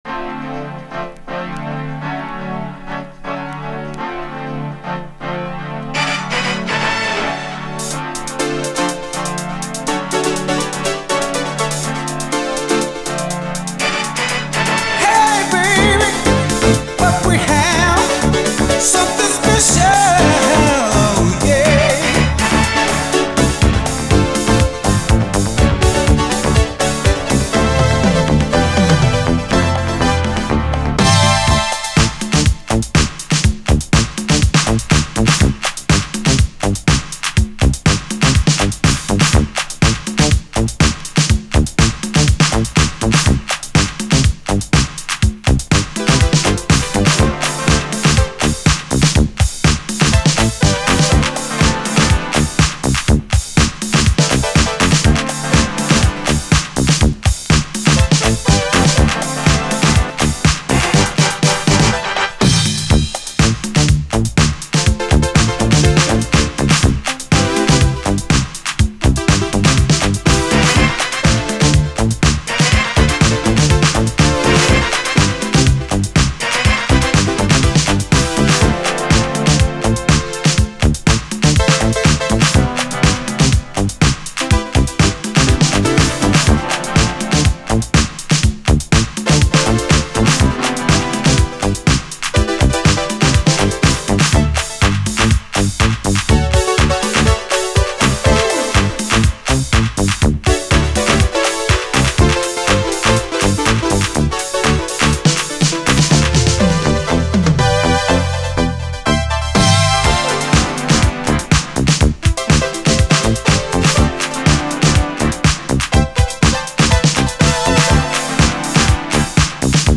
SOUL, 70's～ SOUL, DISCO, DANCE
最高な80’Sダビー・バレアリック・ハウス「(DUB VERSION)」をリコメンド！
初期ハウス・サウンドがダビー＆バレアリックに広がる、最高な80’Sバレアリック・ハウス・トラックです！